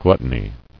[glut·ton·y]